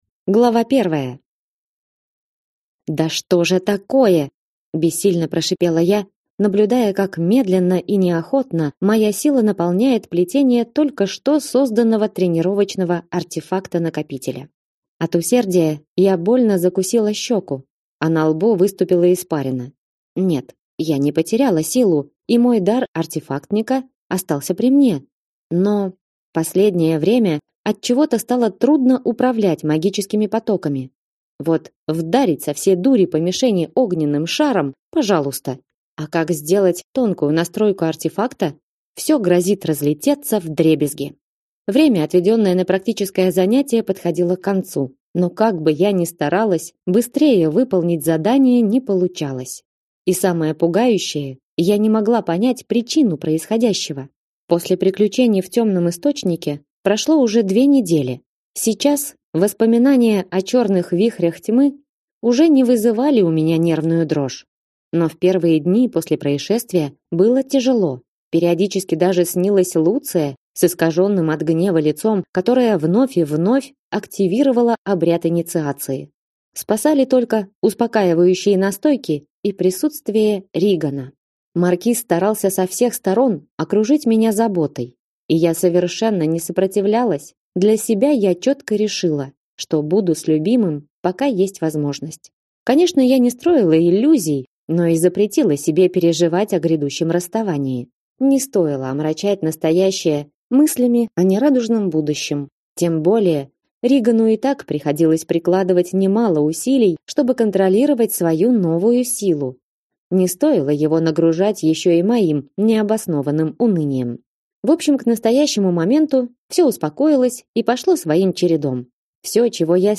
Аудиокнига Покоряя Тьму | Библиотека аудиокниг